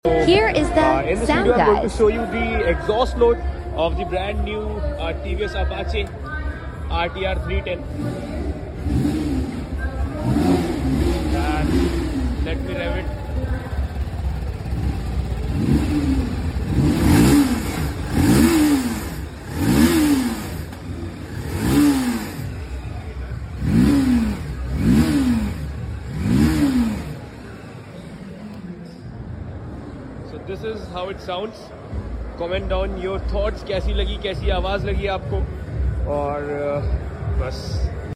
Here is the grunt sound of the newly launched RTR Apache 310.